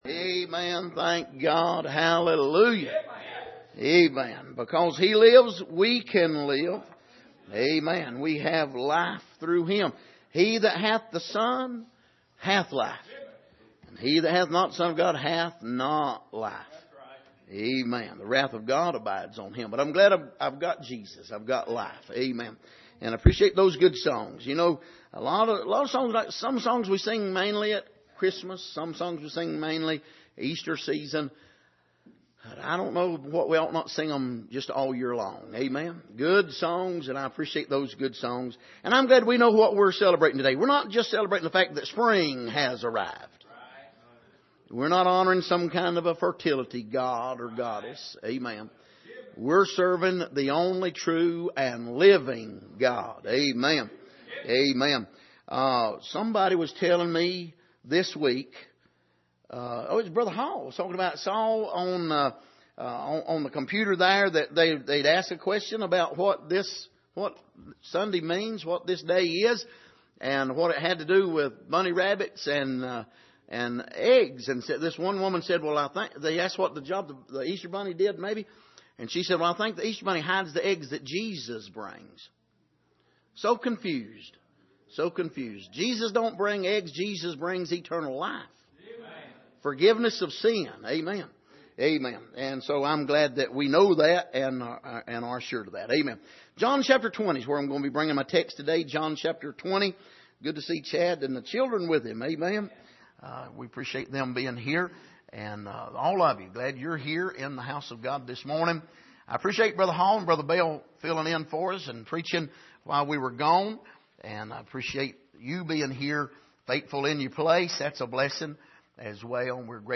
Passage: John 20:1-10 Service: Sunday Morning